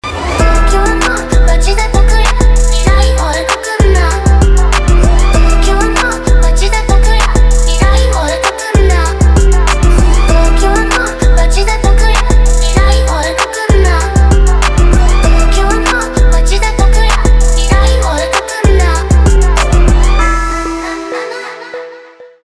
Рэп рингтоны